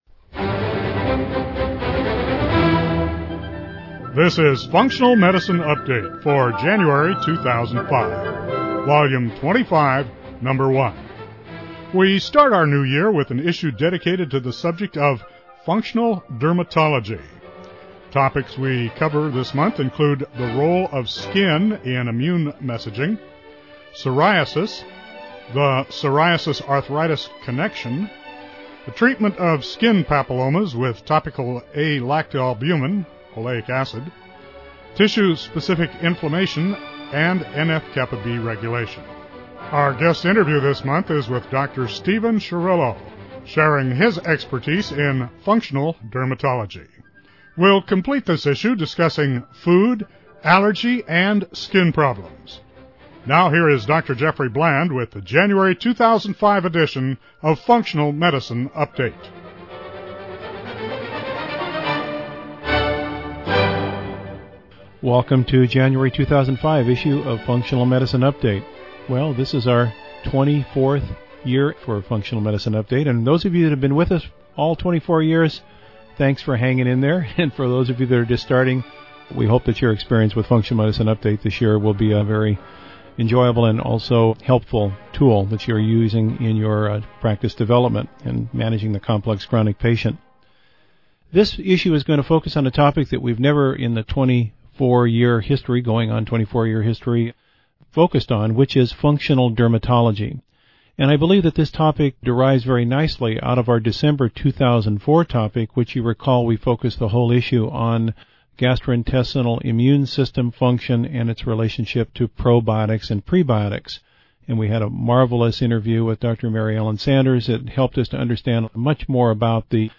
We are fortunate to have a skilled physician for this month’s Clinician/Researcher of the Month interview.